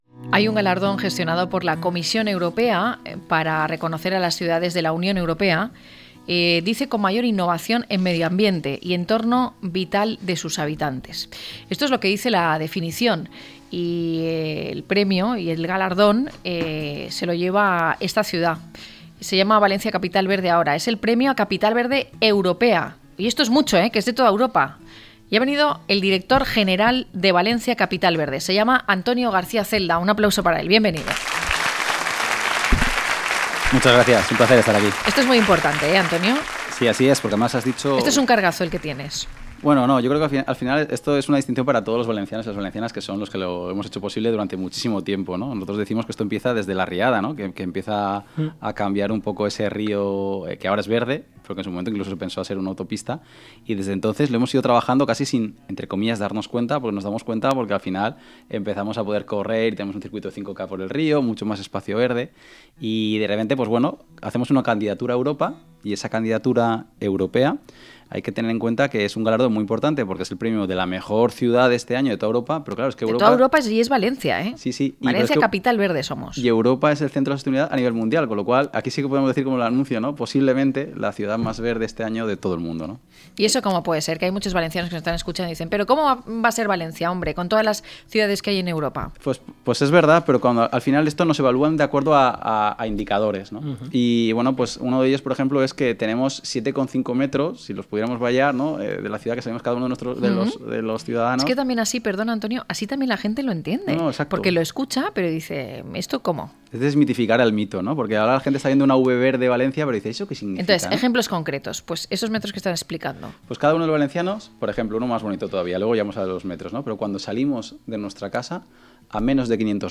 Hablamos con Antonio García Celda, director general de Valencia Capital Verde. Premio Capital Verde Europea: Galardón gestionado por la Comisión Europea para reconocer a las ciudades de la UE con mayor innovación en medioambiente y entorno vital de sus habitantes.